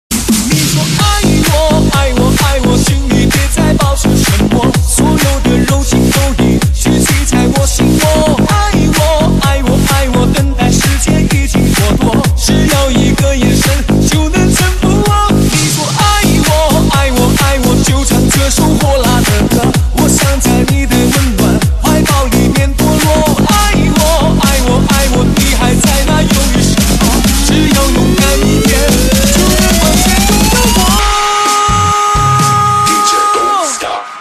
DJ铃声, M4R铃声, MP3铃声 84 首发日期：2018-05-14 21:18 星期一